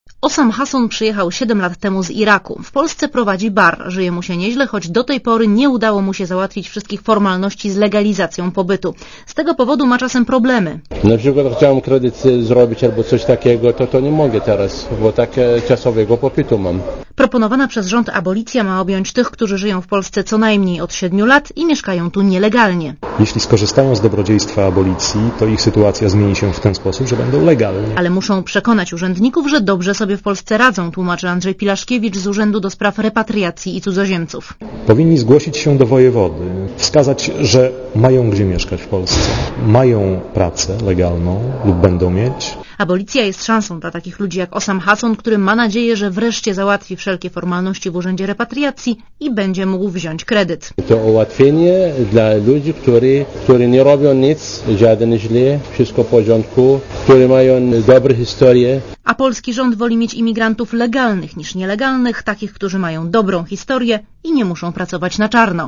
Komentarz audio (256Kb)